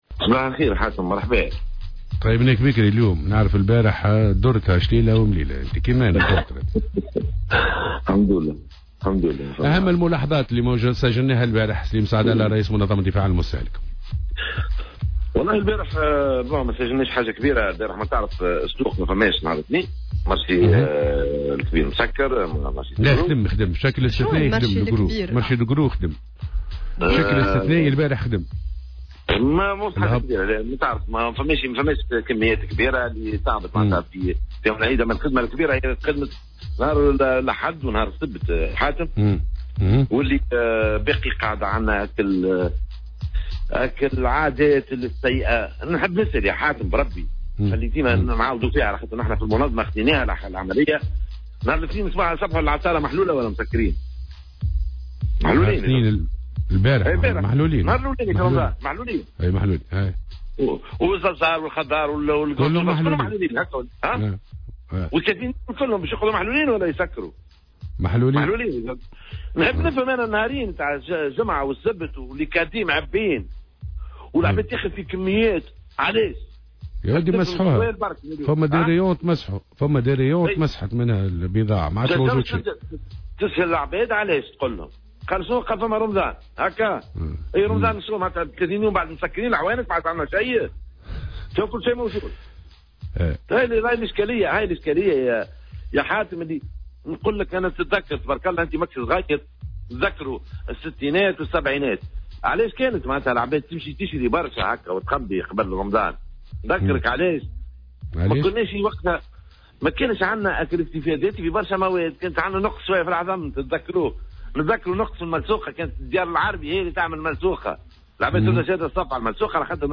Invité de l'émission Sbeh El Ward de ce mardi 7 mai 2019